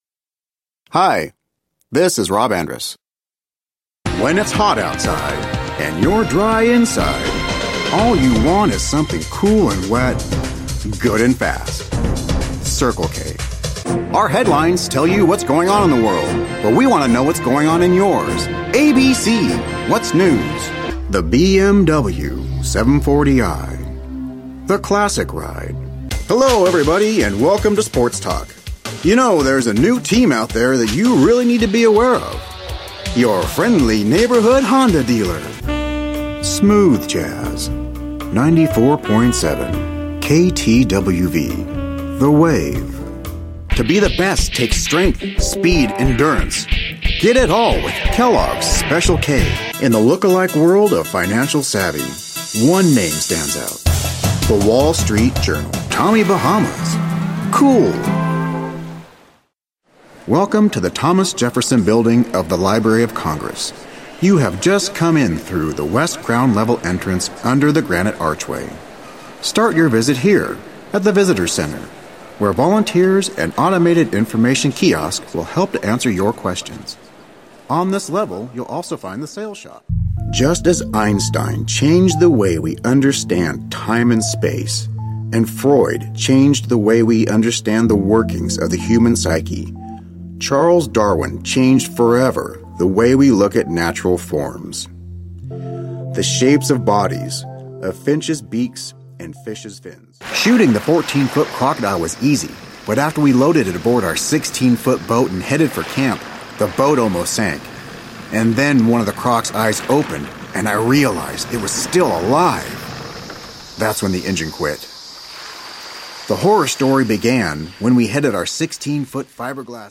Voiceover Reel
Commercial, Host, Intros, Drops, Industrial, Instructional, Narrative.